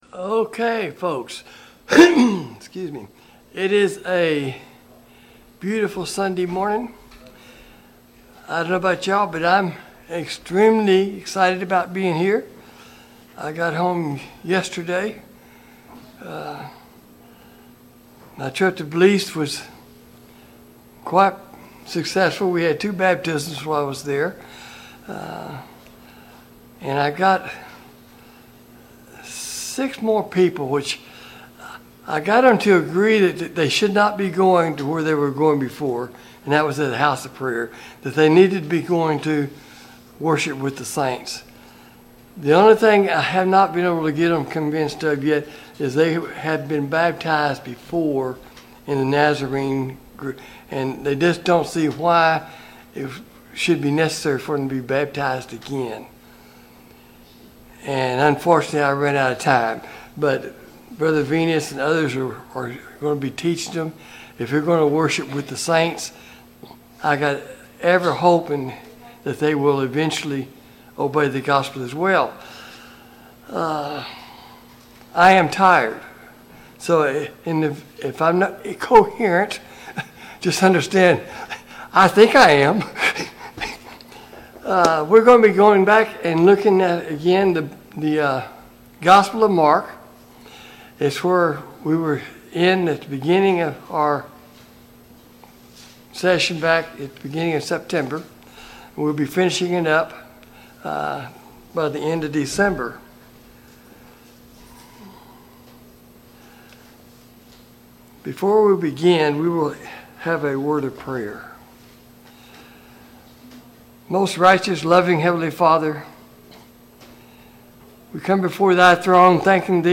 Sunday Morning Bible Class